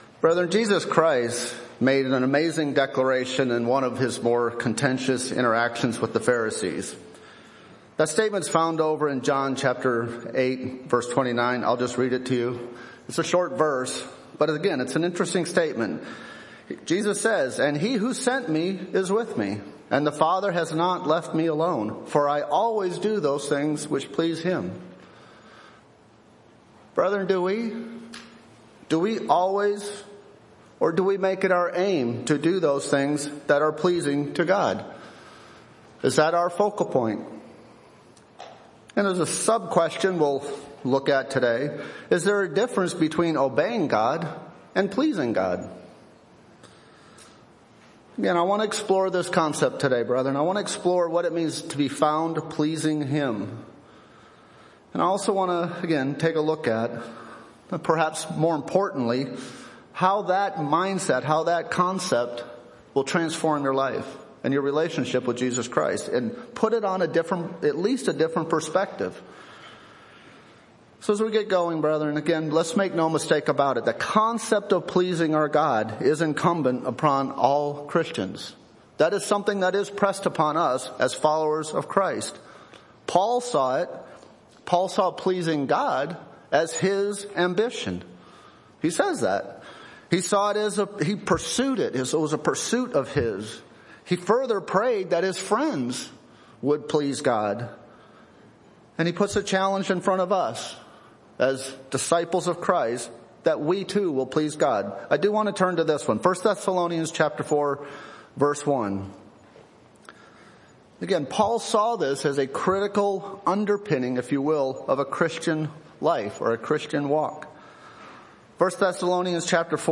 Given in Nashville, TN